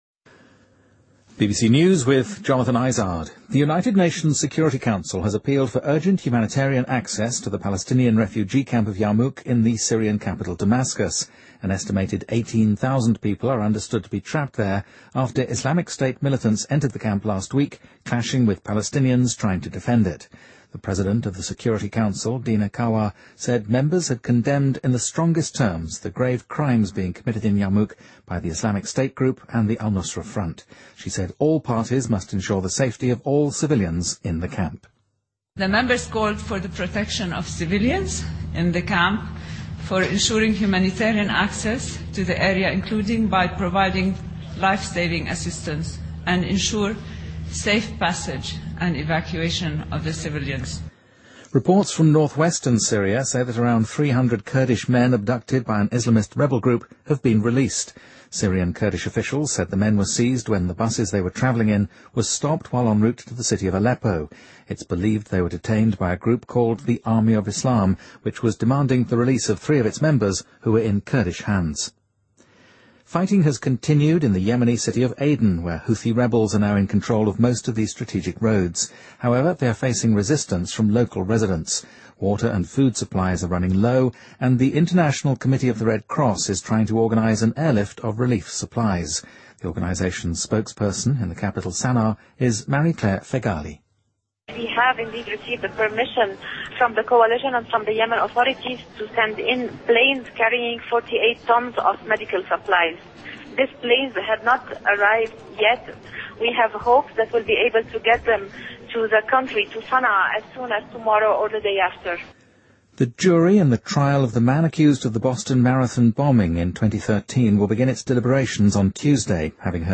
BBC news,安理会呼吁帮助巴勒斯坦难民